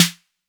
808-Snare20.wav